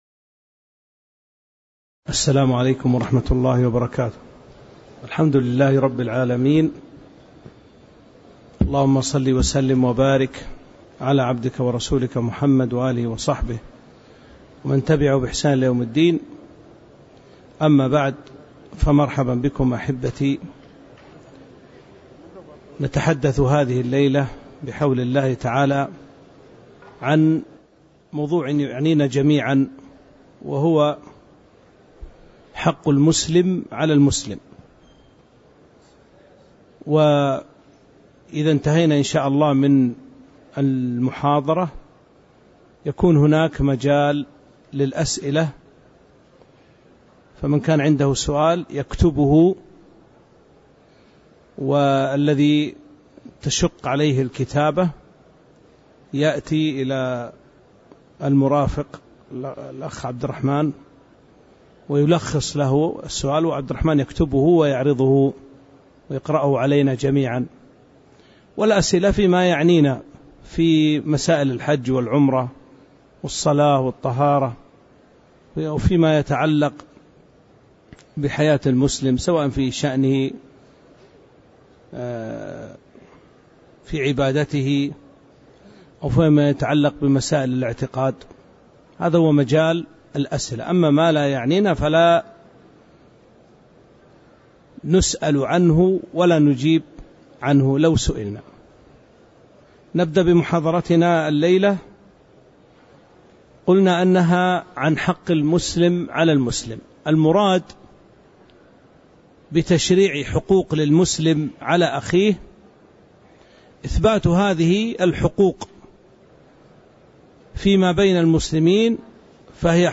تاريخ النشر ٢١ ذو القعدة ١٤٤٥ هـ المكان: المسجد النبوي الشيخ